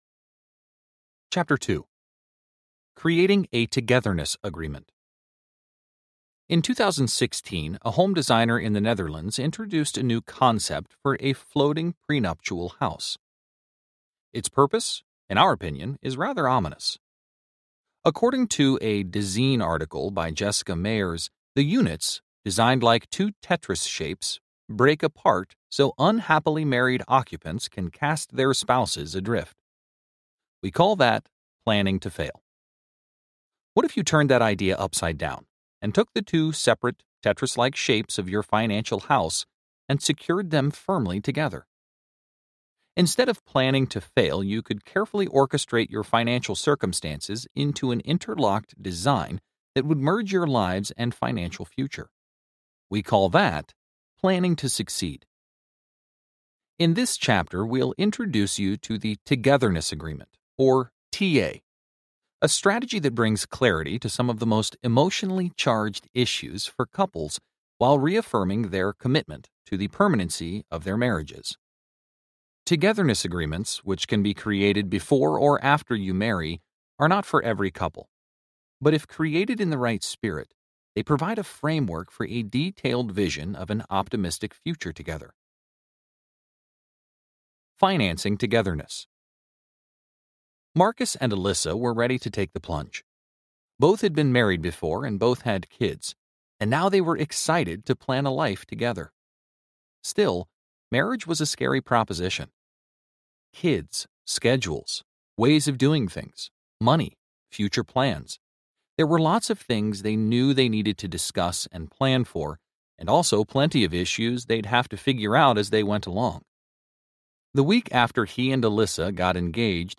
The Smart Stepfamily Guide to Financial Planning Audiobook
6.0 Hrs. – Unabridged